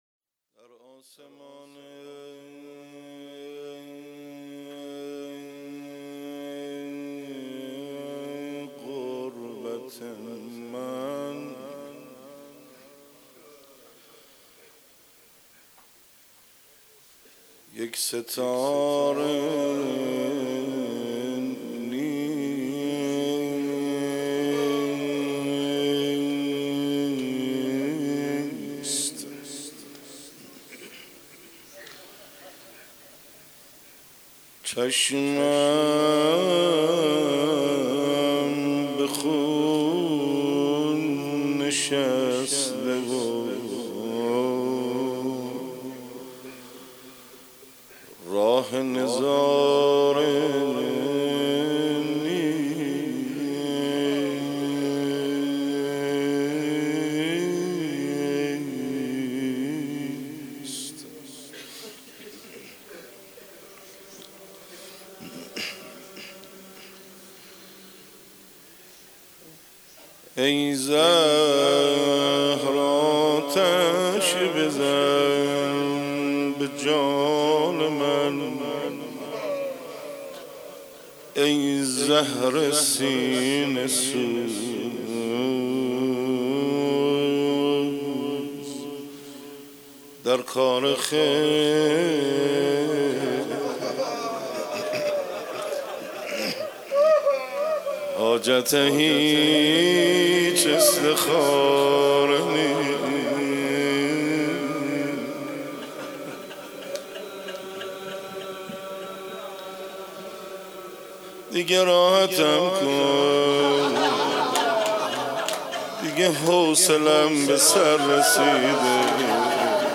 مناسبت : شب دوم محرم
قالب : روضه